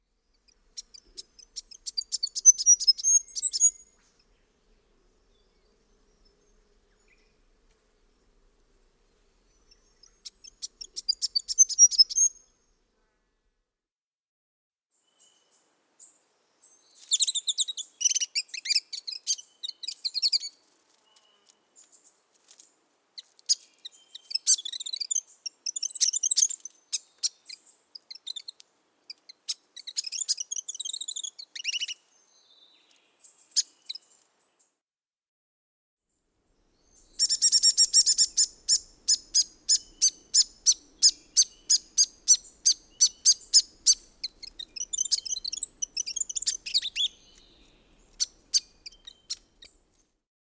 Grey Fantail
Songs & Calls